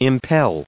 added pronounciation and merriam webster audio
1596_impel.ogg